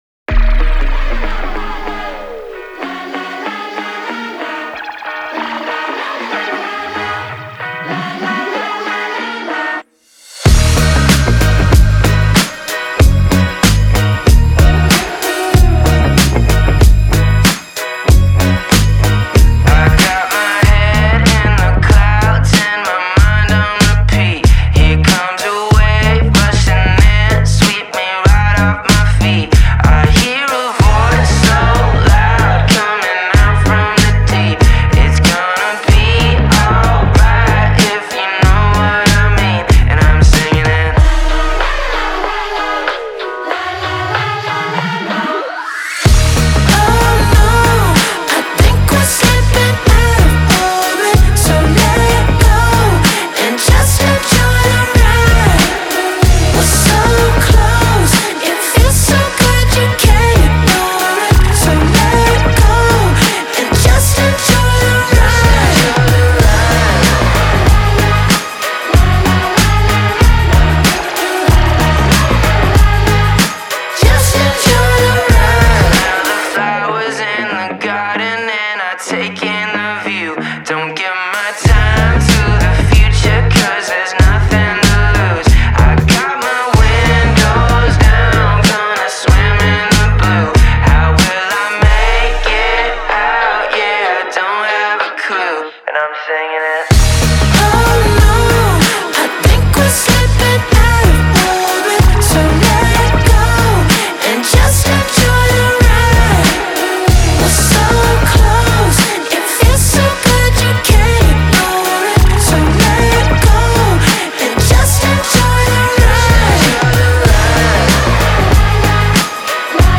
BPM94-94
Audio QualityPerfect (High Quality)
Indie pop song for StepMania, ITGmania, Project Outfox
Full Length Song (not arcade length cut)